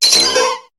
Cri de Ptitard dans Pokémon HOME.